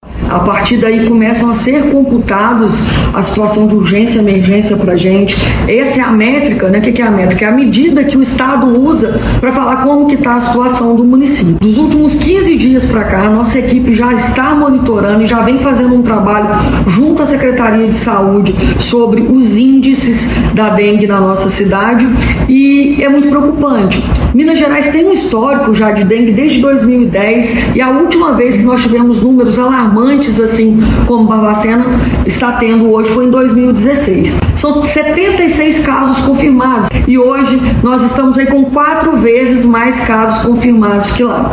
No pronunciamento, a secretária falou que nessa semana estão previstas ações como mutirão, testes rápidos nas Unidades Básicas de Saúde e preparação de portas de entrada para atender os casos suspeitos.